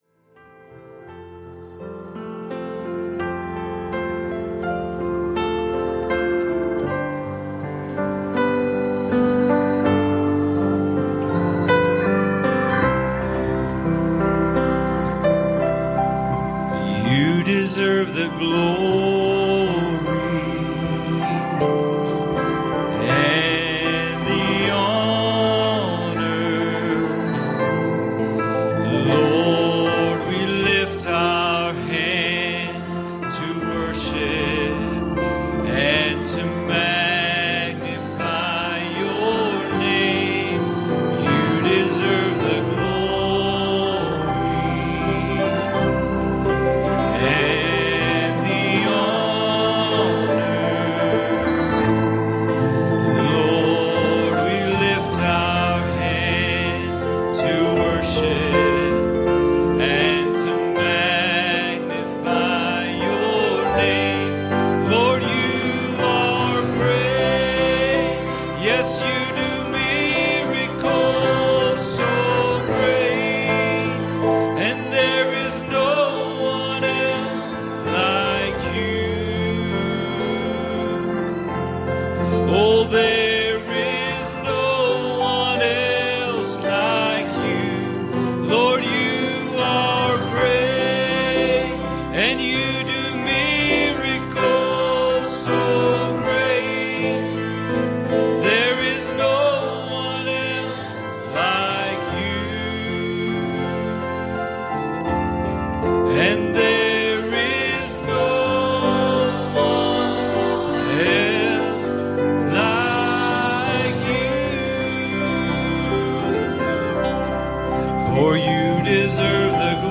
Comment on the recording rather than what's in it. Passage: 1 Chronicles Service Type: Wednesday Evening